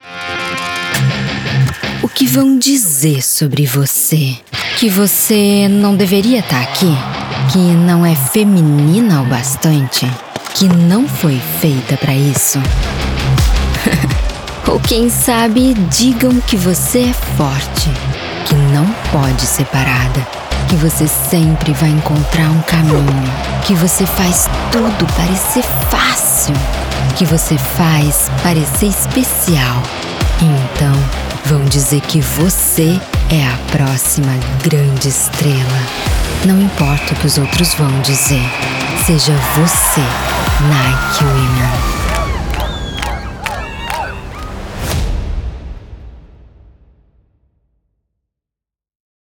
I'm professional Brazilian voiceover talent. My voice is natural, young, fun, confident, pleasant.
Sprechprobe: Industrie (Muttersprache):